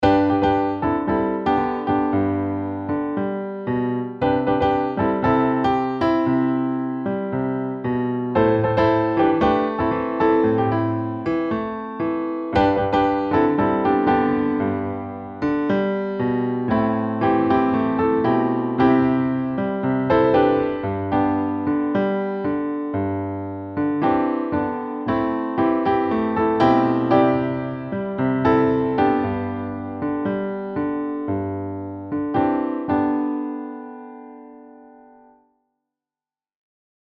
Piano Demo